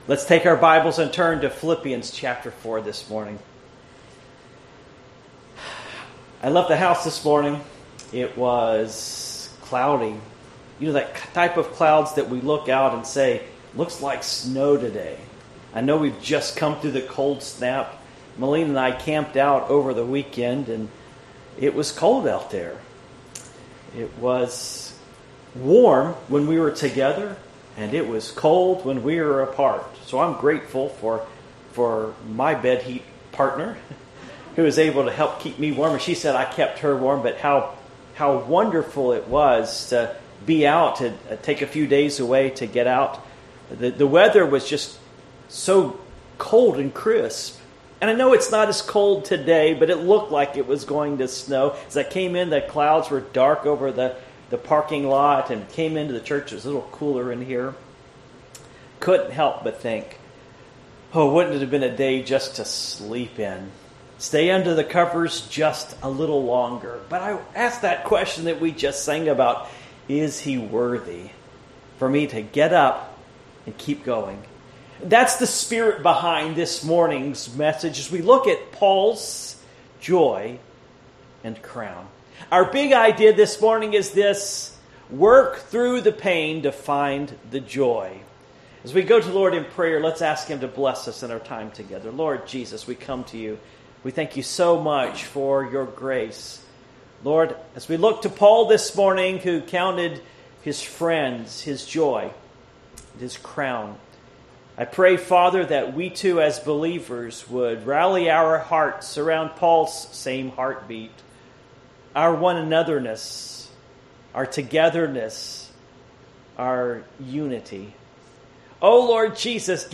Passage: Philippians 4:1-3 Service Type: Morning Worship